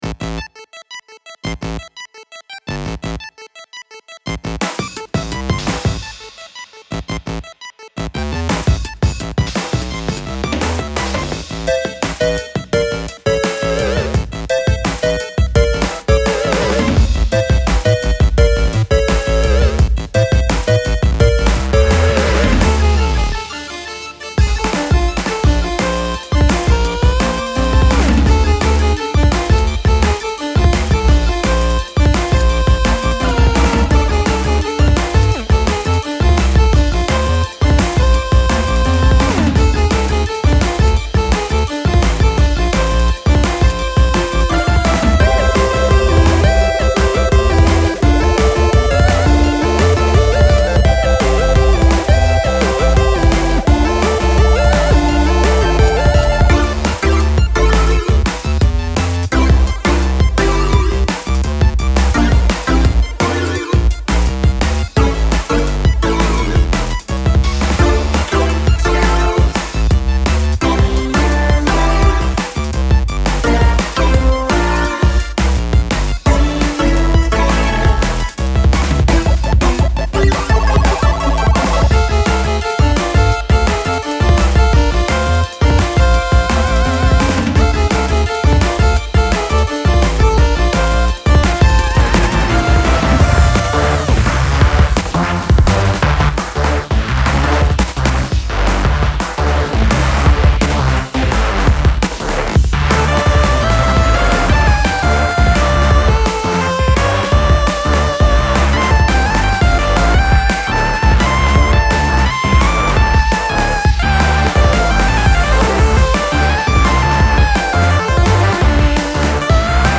groovy chiptune DnB combo with live drums
vocoded voices